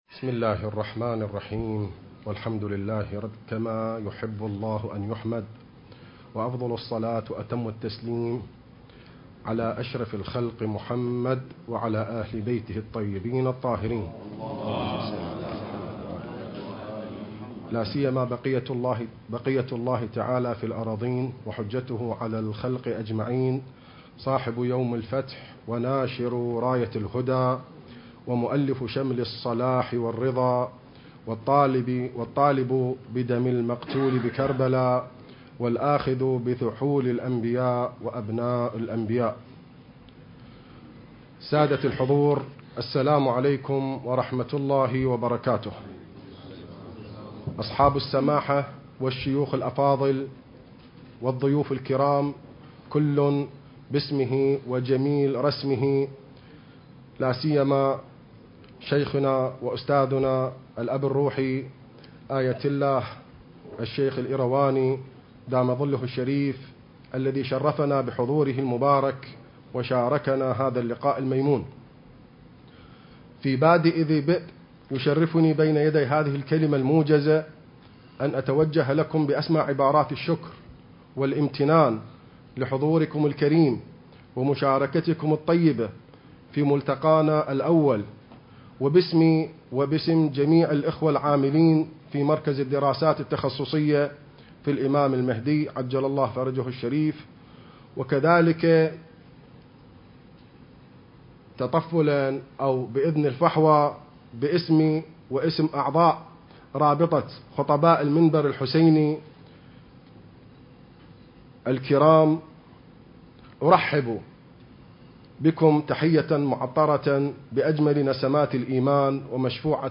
المكان: العتبة العلوية المقدسة